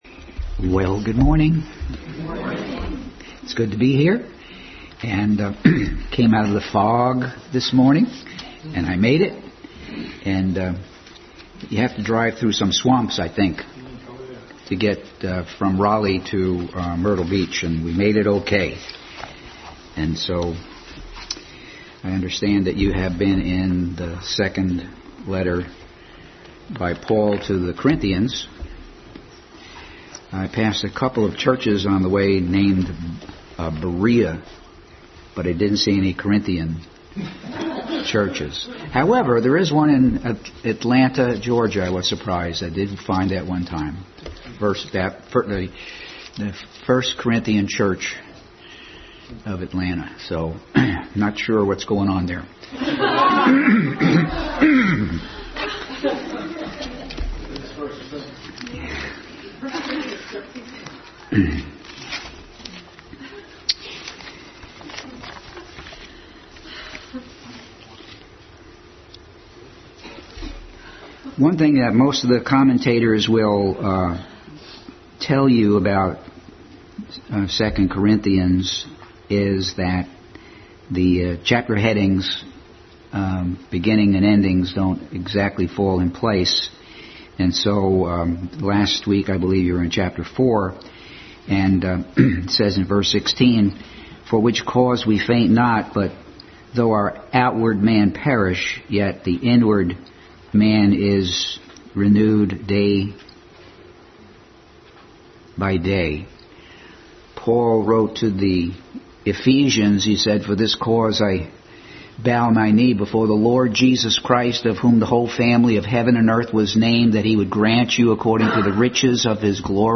Adult Sunday School Class continued study in 2 Corinthians.